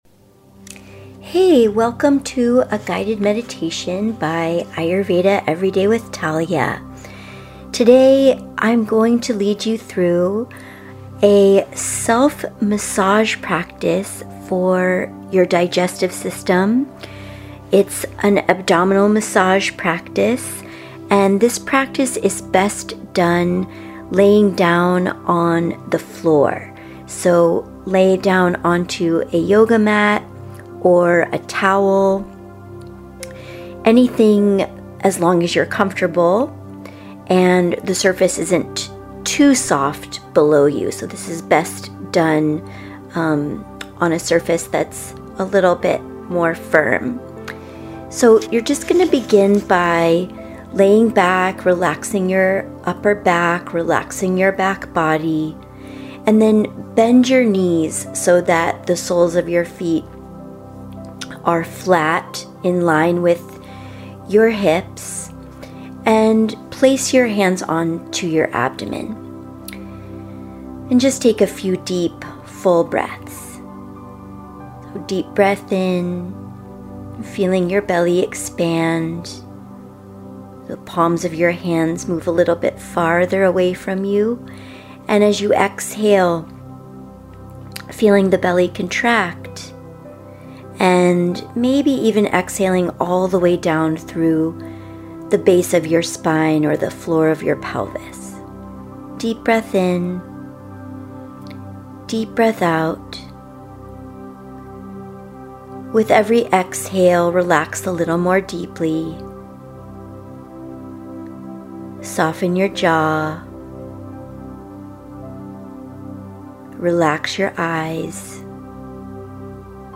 Digestive-Massage-Meditation.mp3